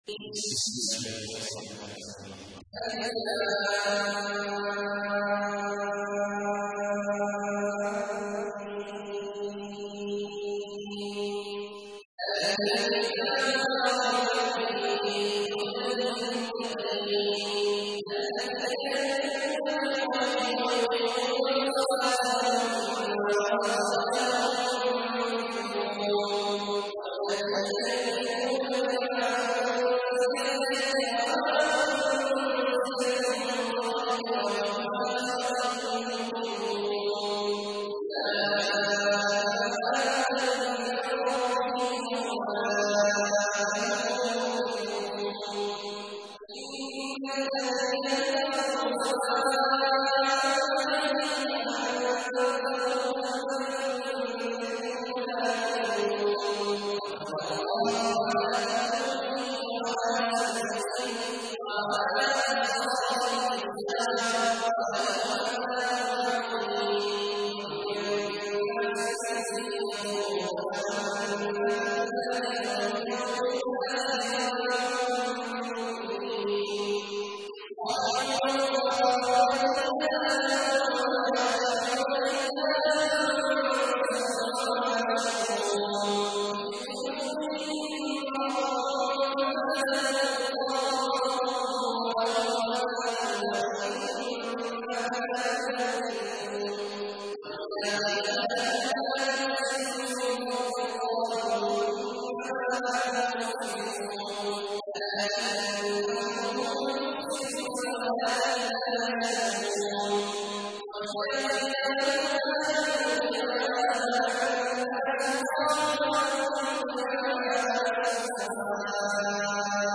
تحميل : 2. سورة البقرة / القارئ عبد الله عواد الجهني / القرآن الكريم / موقع يا حسين